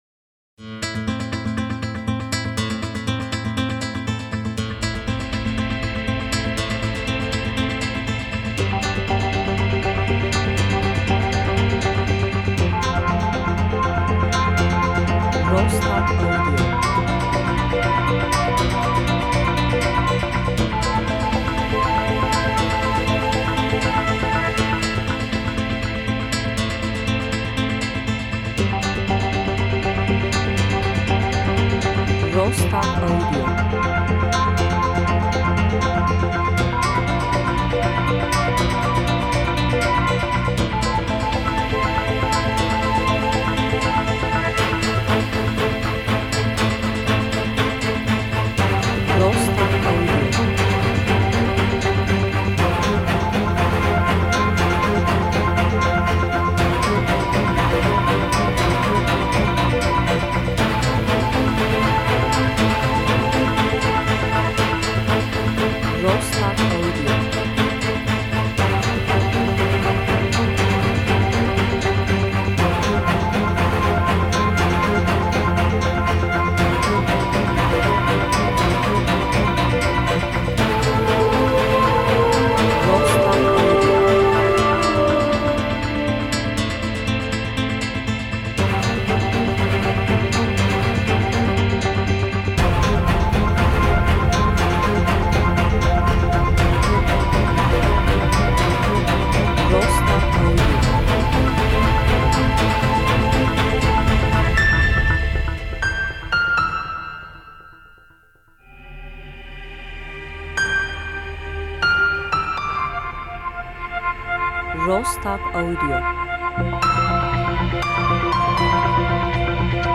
hareketli müzik
enstrümantal